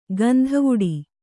♪ gandhavuḍi